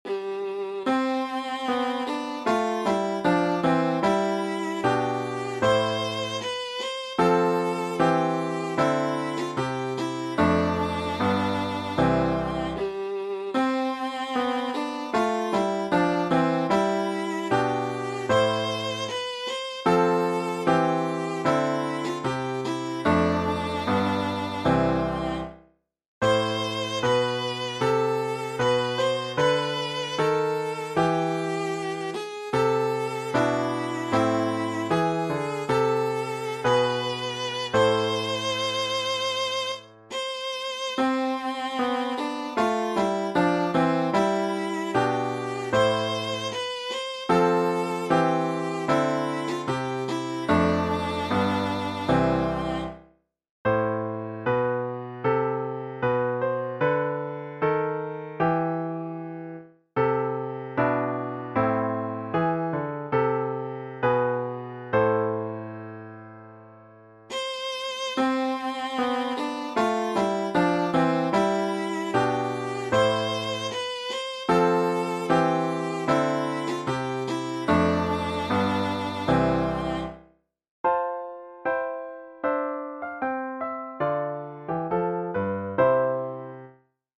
The Minstrel Boy (Moore) | Free Easy Viola Solo
Free printable sheet music for The Minstrel Boy by Thomas Moore for Easy Viola Solo with Piano Accompaniment.
minstrel-boy-viola.mp3